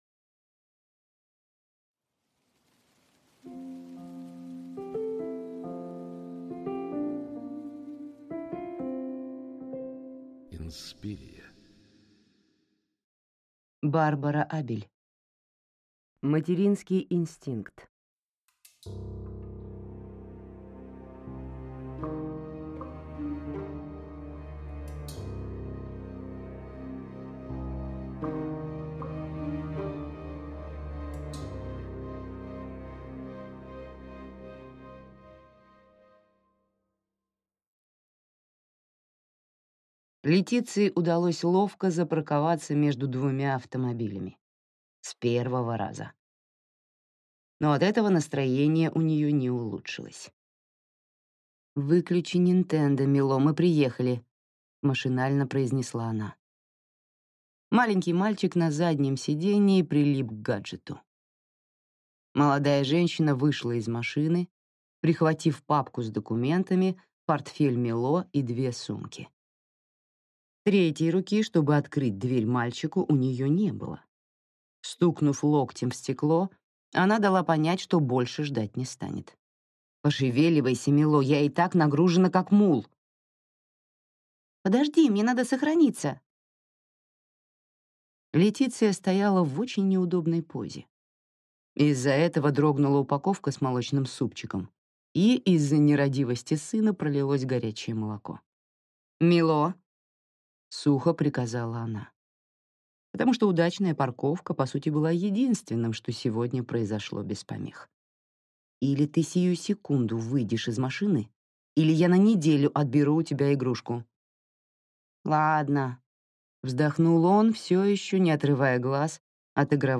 Аудиокнига Инстинкт матери | Библиотека аудиокниг